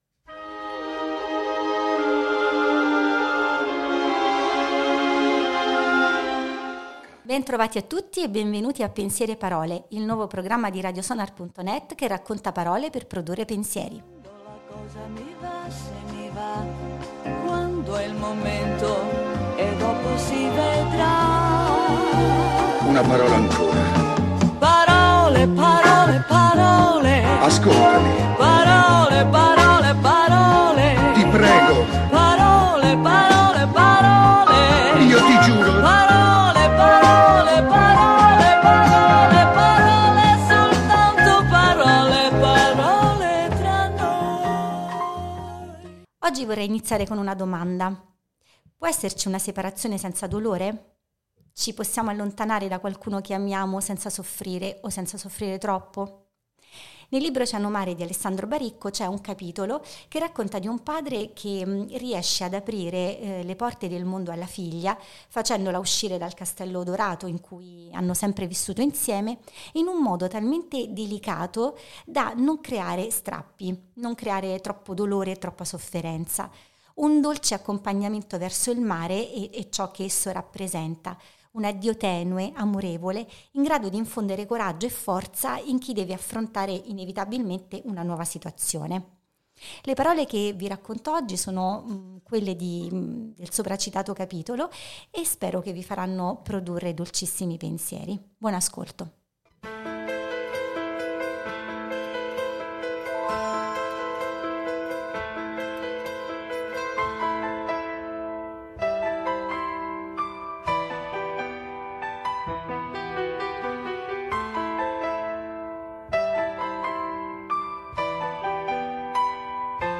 È il tema di questa lettura, l’esplorazione di un viaggio per affrontare le proprie fragilità reso poetico dall’amore di un padre, sono le pagine di Oceanomare di Baricco che scivolano leggere sopra di noi!